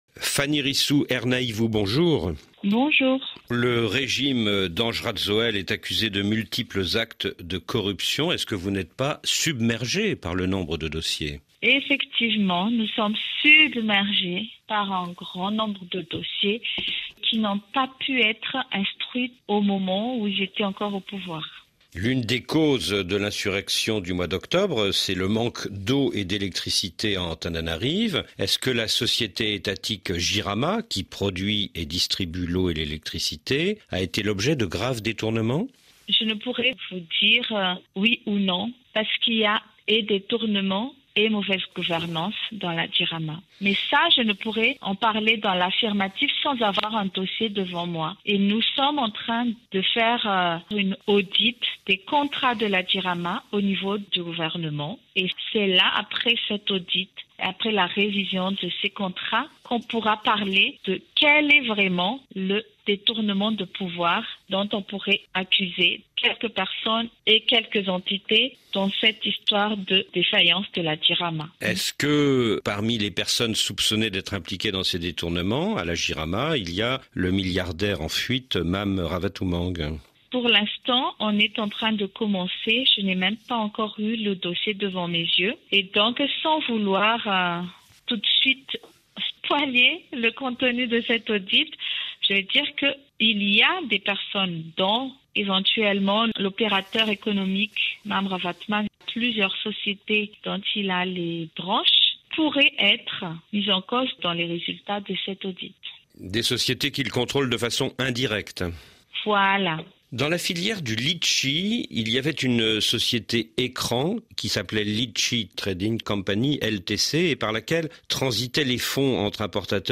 interview-fanirisoa-ernaivo-rfi-dec-2025.mp3